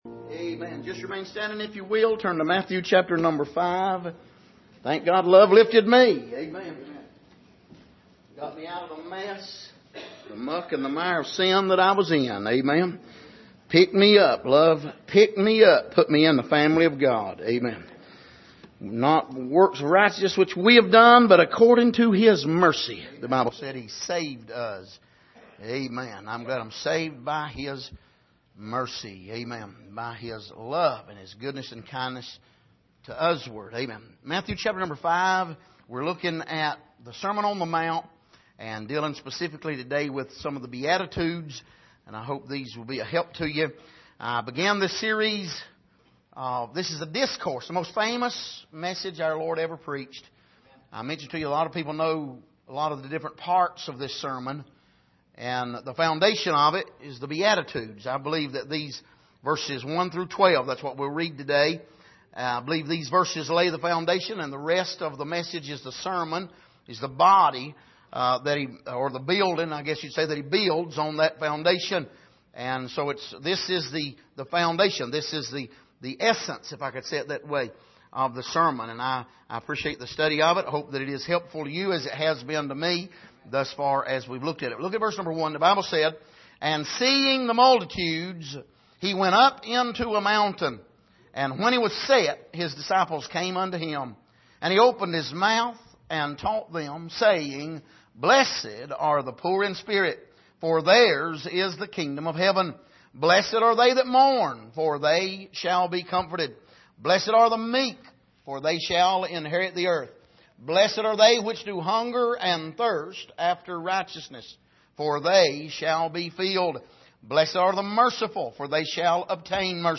Passage: Matthew 5:1-12 Service: Sunday Morning